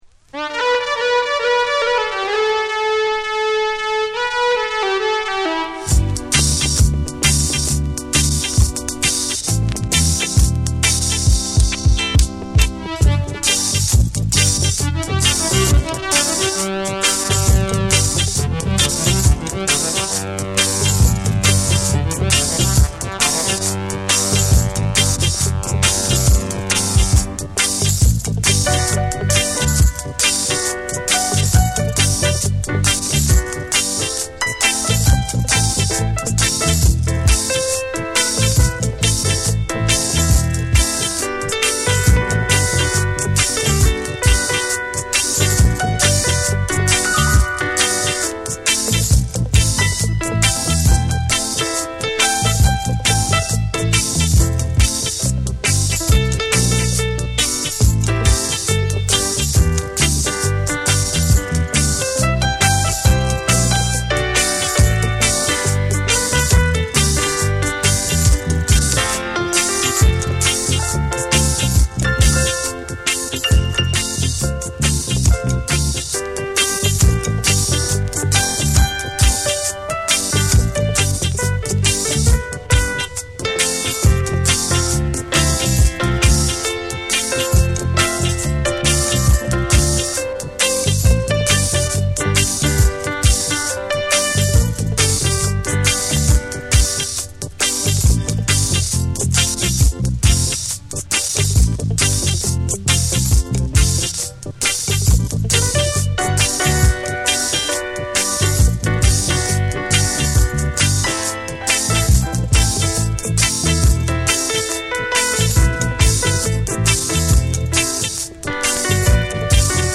重量感あるベースと生々しいミキシングが際立つ
幻想的に響かせるメロウ・ダブ
ゆったりとしたグルーヴが心地よい